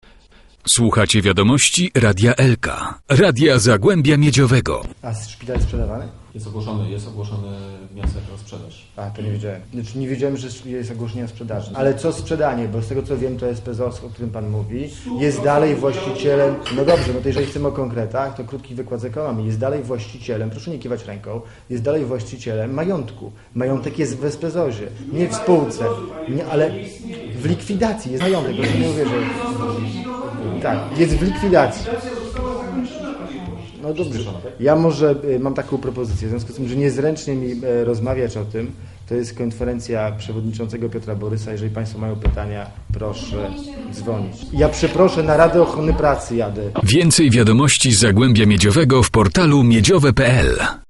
Przykład Regionalnego Centrum Zdrowia w Lubinie. Pytany na wczorajszej konferencji prasowej o własne stanowisko wobec planowanej prywatyzacji tej placówki, poseł z rozbrajającą szczerością odpowiedział pytaniem.